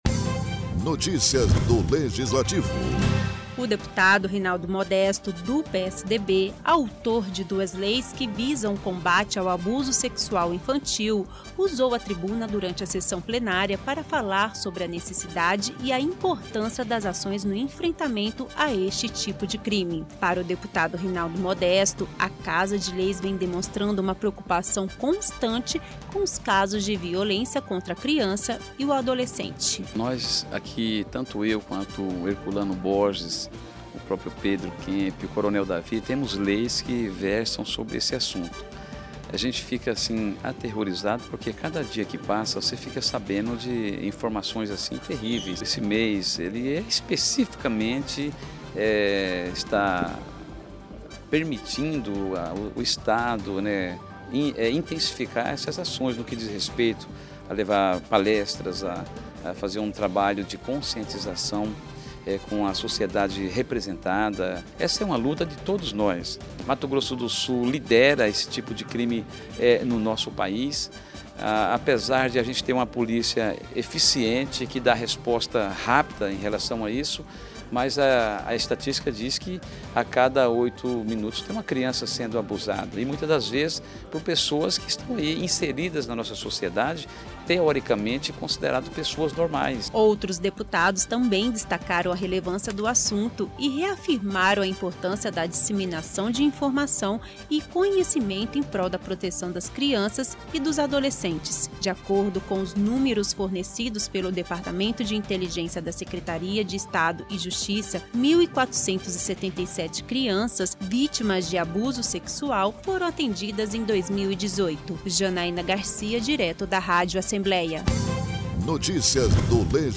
Durante a sessão ordinária desta quinta-feira, deputados destacam a importância das ações no enfrentamento ao abuso sexual infantil.